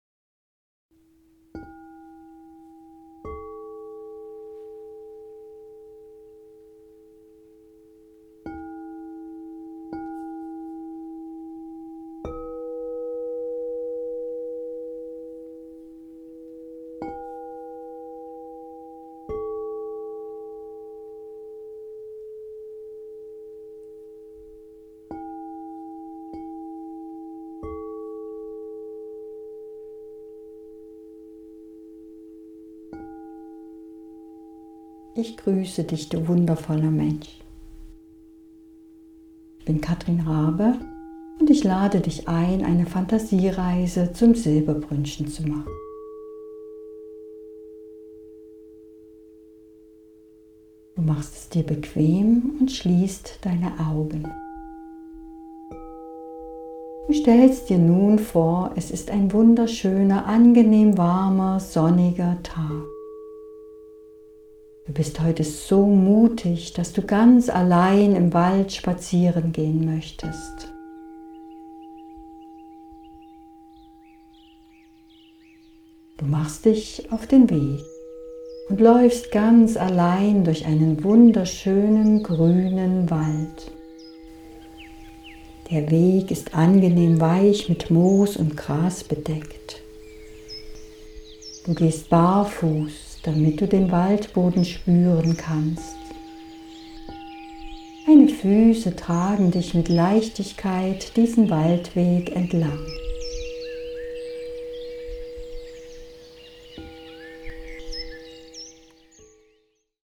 Fantasiereise zum Probehören
Meditation "Fantasiereise zum Silberbrünnchen" kaufen